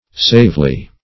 savely - definition of savely - synonyms, pronunciation, spelling from Free Dictionary
savely - definition of savely - synonyms, pronunciation, spelling from Free Dictionary Search Result for " savely" : The Collaborative International Dictionary of English v.0.48: Savely \Save"ly\, adv.